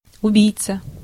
Ääntäminen
IPA : /əˈsæsɪn/